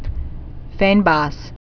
(fānbŏs)